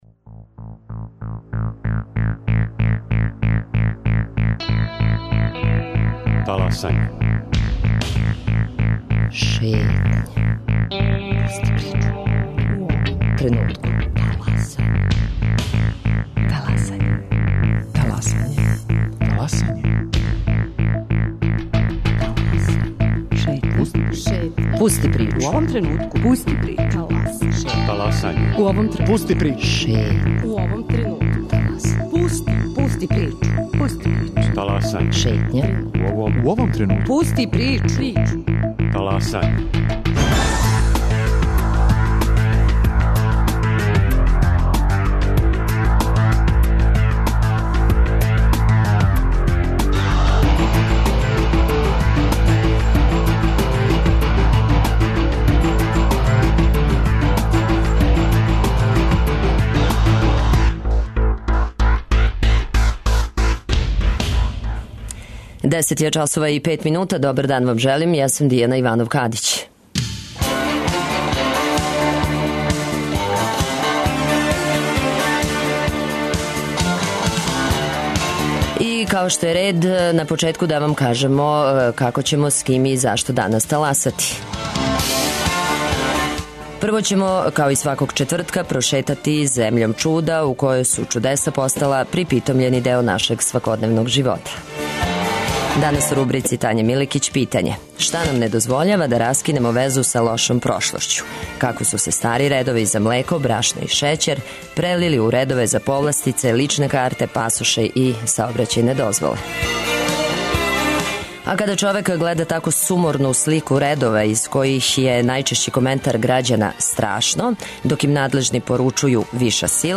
Да ли позоришна представа може у човеку да пробуди толеранцију и жељу да пружи отпор насиљу, дискриминацији, предрасудама и сваком облику некултуре? Колико је духовно сиромаштво опасније од економског? Гост: Егон Савин, редитељ.